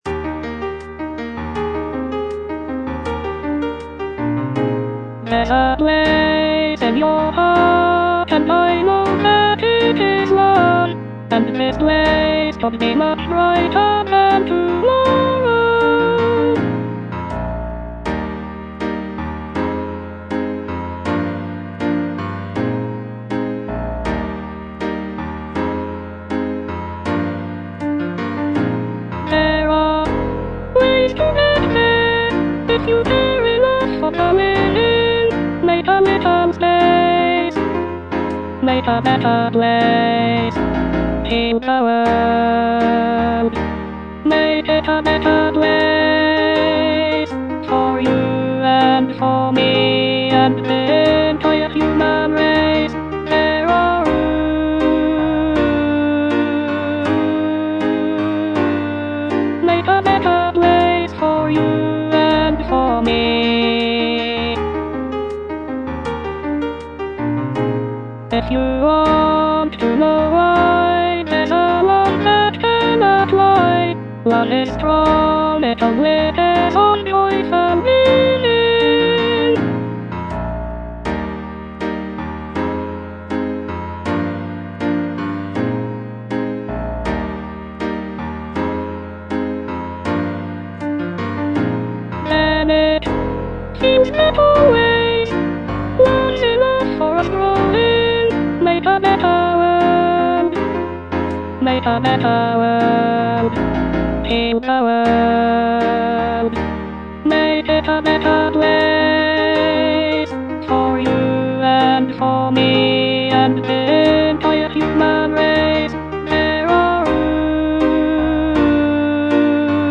Alto II (Voice with metronome)